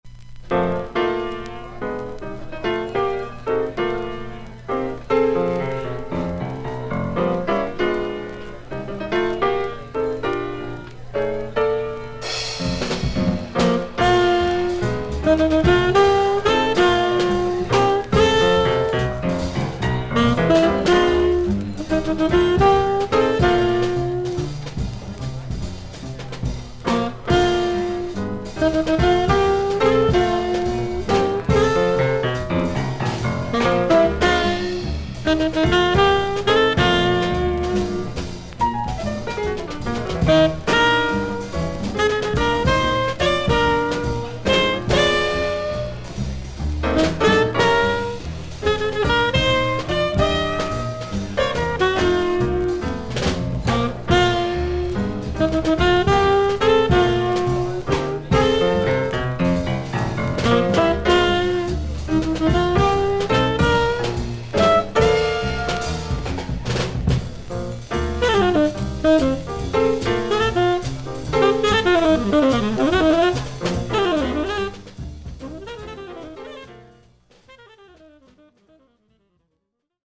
アメリカ盤 / 12インチ LP レコード / モノラル盤
少々軽いパチノイズの箇所あり。少々サーフィス・ノイズあり。クリアな音です。
ジャズ・ピアニスト。
ニュー・ヨーク、ファイヴ・スポット・カフェでライヴ録音。